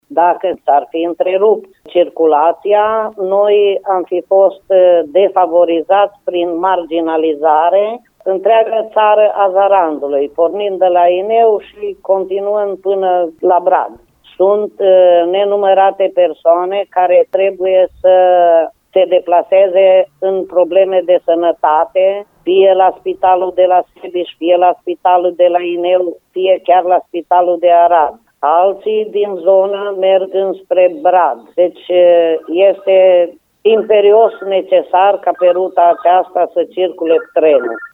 Primarul comunei Gurahonț, Ana Lenuța Moțica, explică ce înseamnă repunerea în funcțiune a trenului Arad – Brad pentru locuitorii din Țara Zarandului: